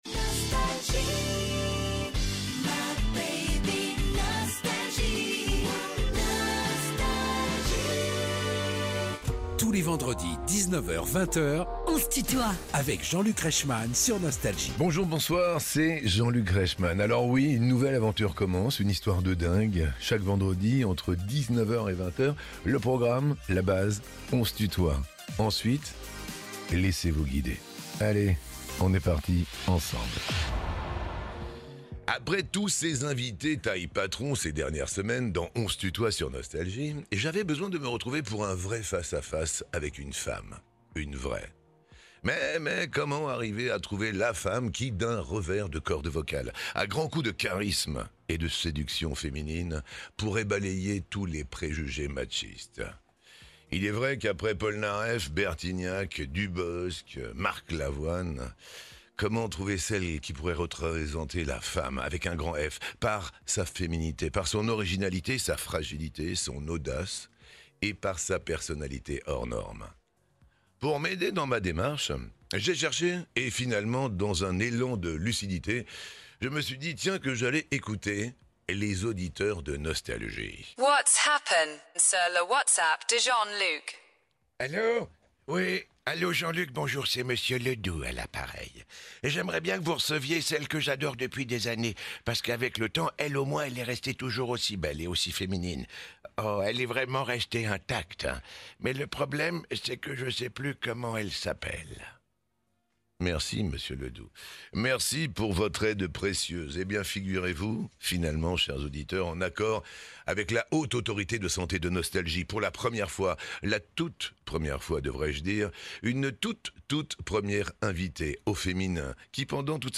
Jeanne Mas est en tournée pour fêter ses 40 ans de carrière et nous présente son nouvel album "Mon Elix" ~ Les interviews Podcast